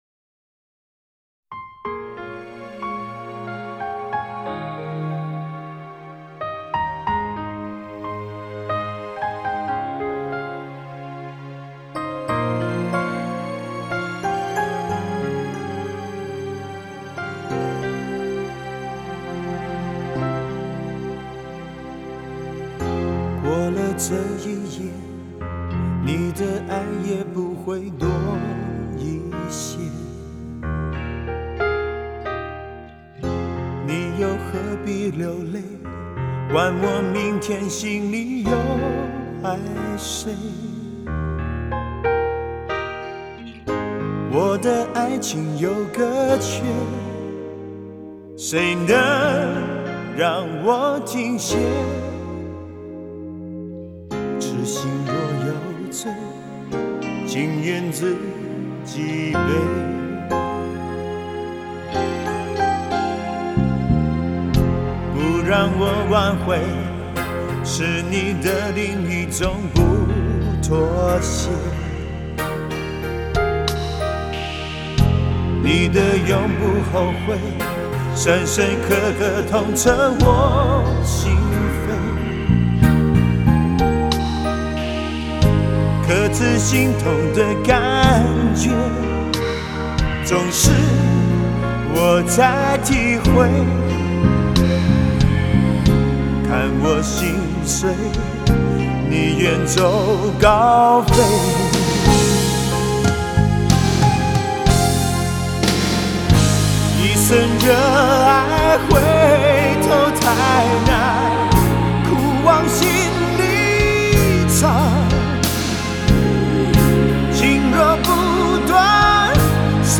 流行 收藏 下载